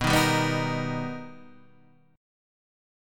B+M7 chord